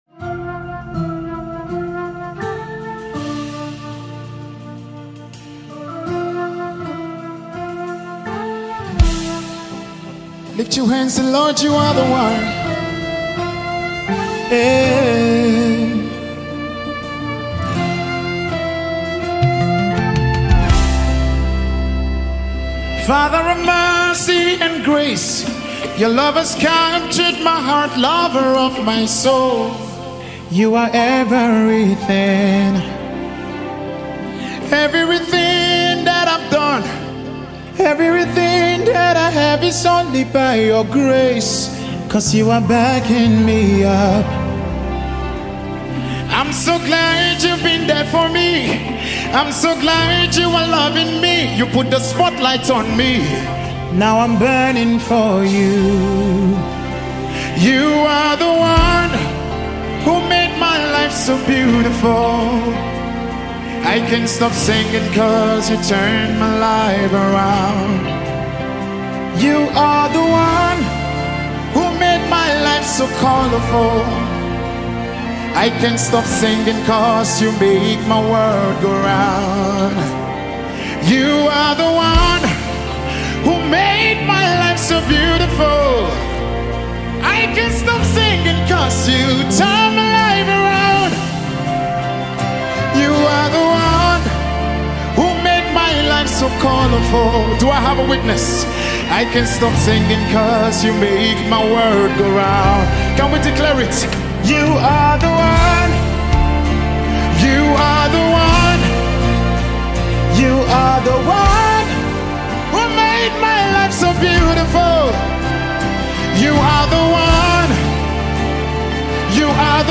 Nigerian Gospel minister and songwriter
songs filled with Gospel worship